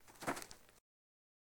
PixelPerfectionCE/assets/minecraft/sounds/mob/rabbit/hop3.ogg at mc116